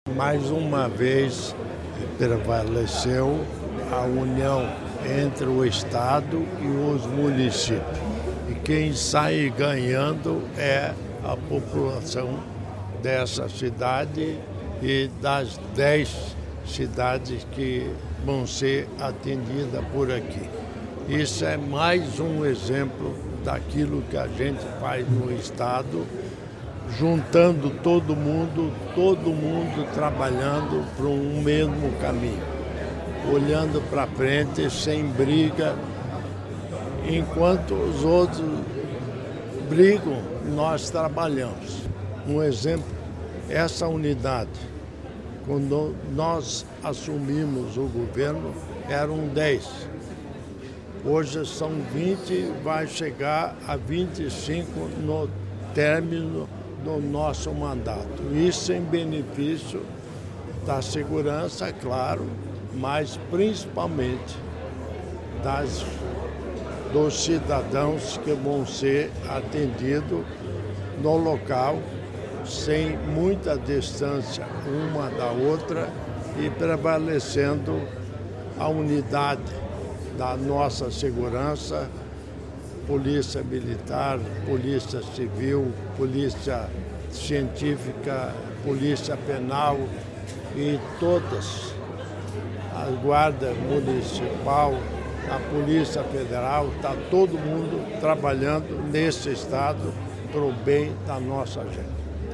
Sonora do governador em governador em exercício Darci Piana sobre a nova sede da Polícia Científica de Telêmaco Borba